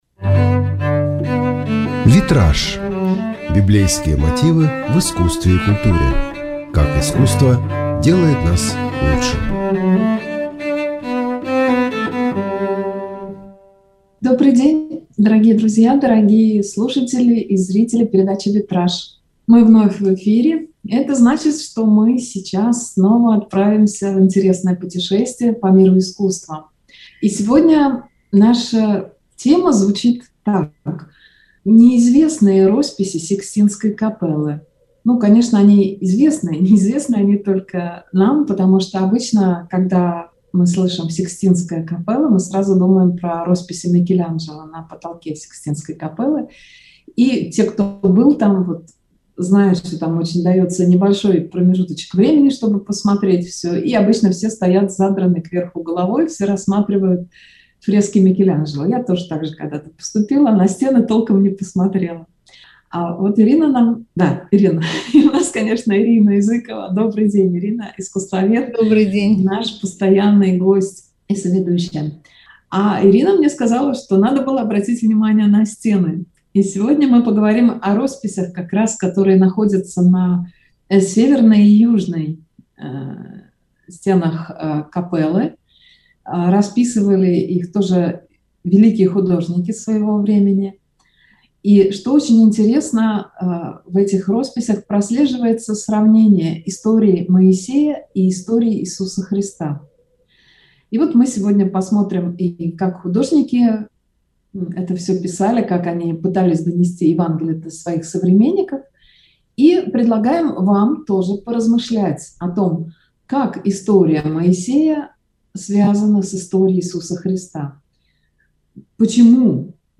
Венцом творения Сикстинской капеллы называют фрески Микеланджело. Однако росписью зала занимались самые именитые художники того времени: Сандро Боттичелли, Доменико Гирландайо, Пьетро Перуджино, Козимо Роселли. Поговорим об их работах с искусствоведом